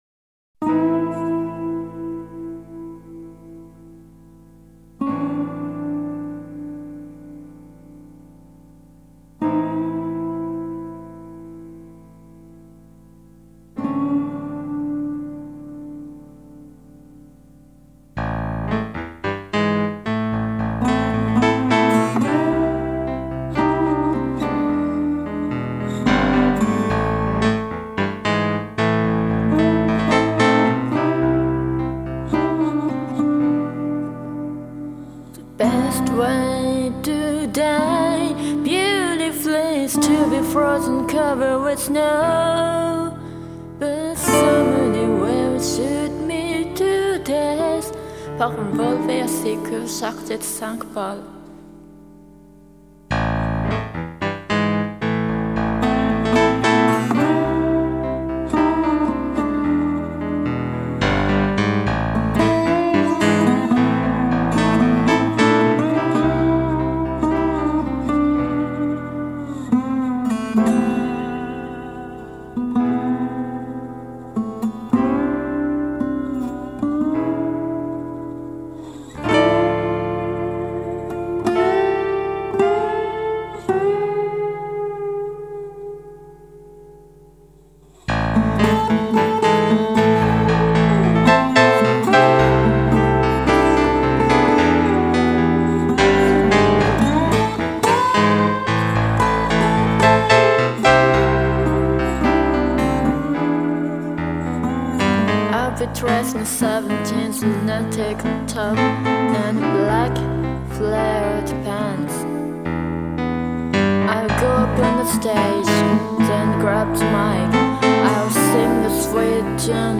Two girls, an accordian, and a guitar.